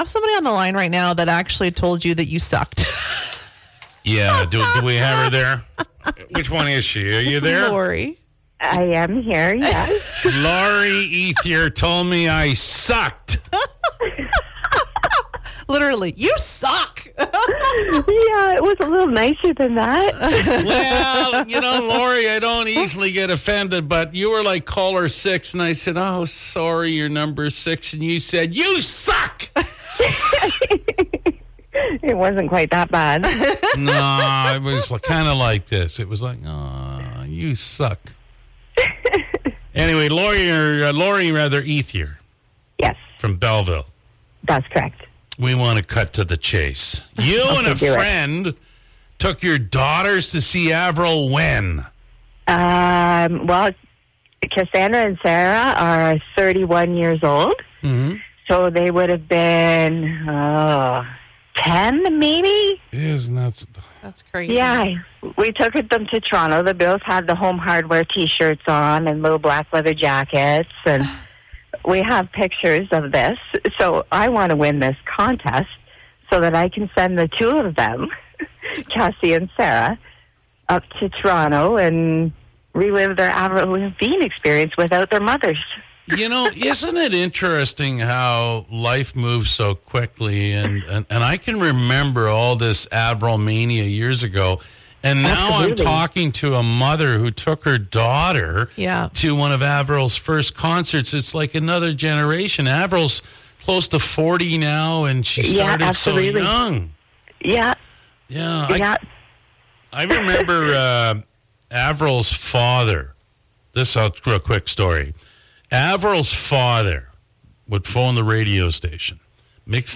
Funny Caller: “You Suck”
How it works is that we play a touchtone, and then we take the first 5 callers.